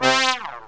SYNTH GENERAL-4 0009.wav